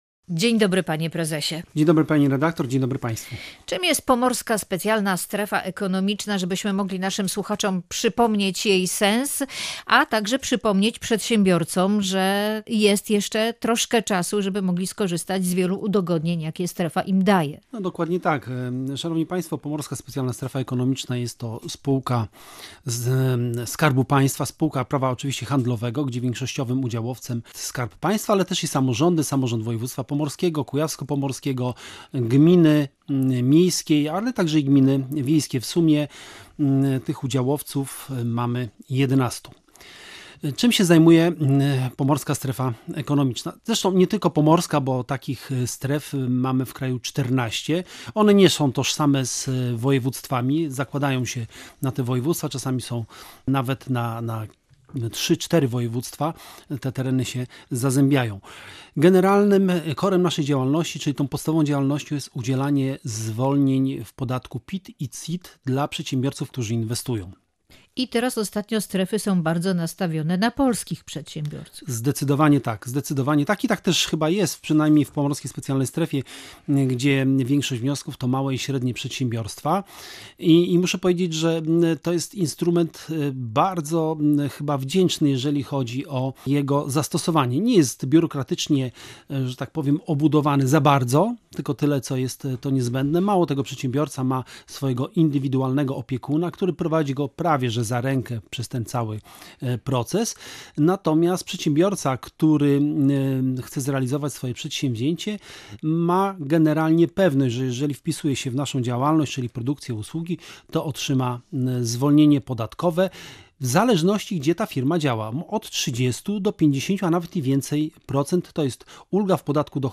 Gość Radia Gdańsk